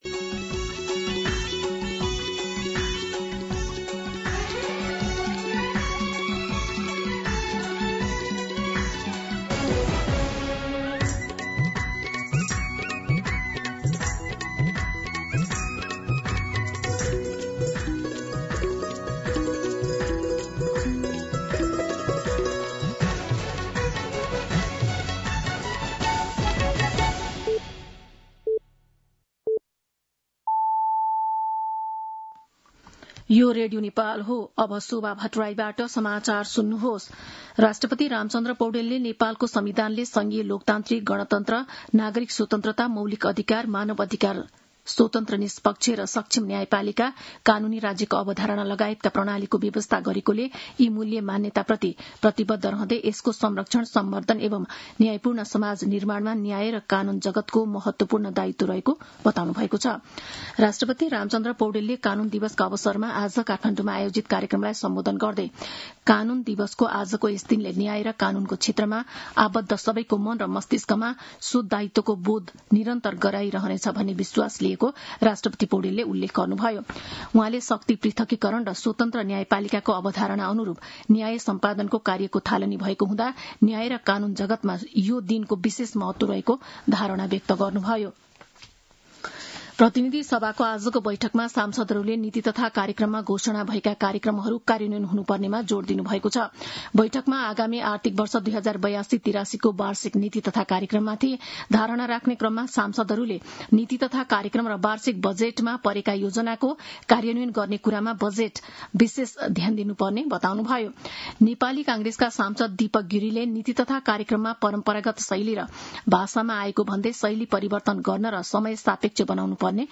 दिउँसो ४ बजेको नेपाली समाचार : २६ वैशाख , २०८२